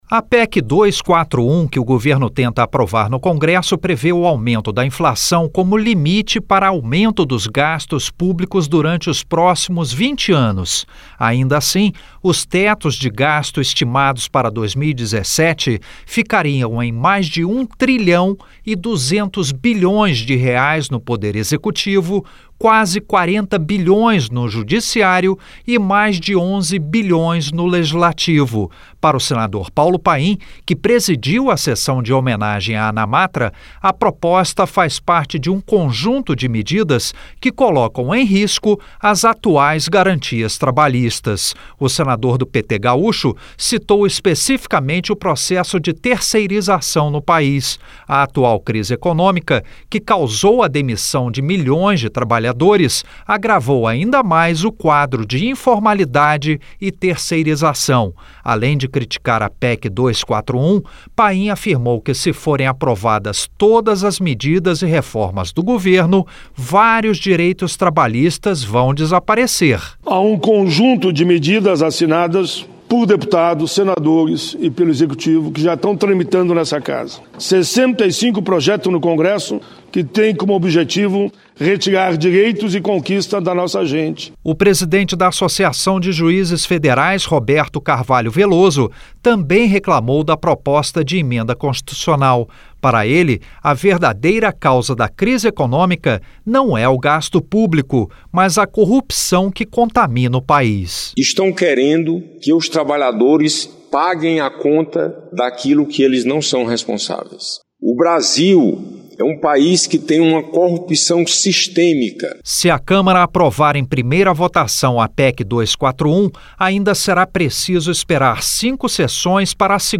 Eles discutiram o assunto nesta segunda-feira (10) durante sessão especial do Senado para homenagear os 40 anos da Associação Nacional dos Magistrados da Justiça do Trabalho (Anamatra).